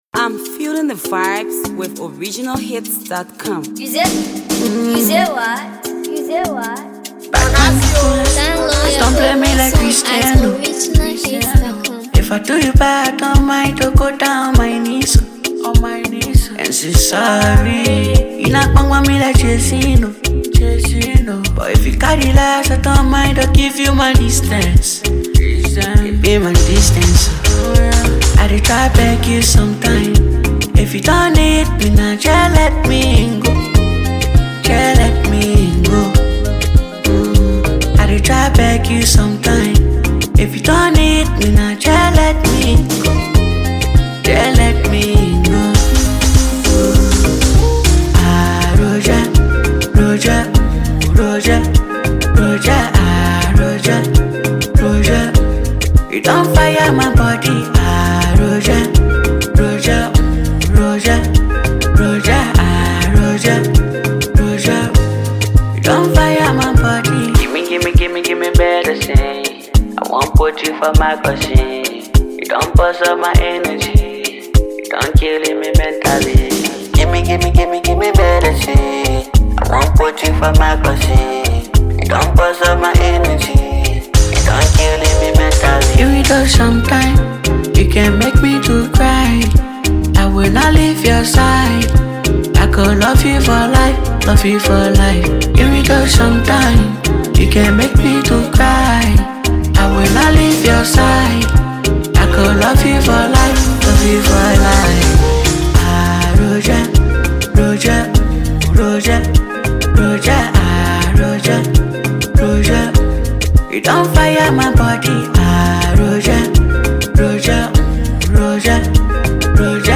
AfroAfro PopLATEST PLAYLISTMusicRnB